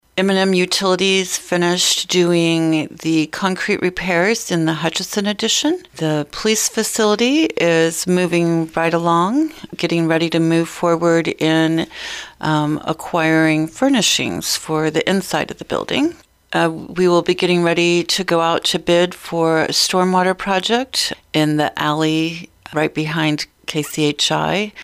City Administrator Roze Frampton discussed some of the projects.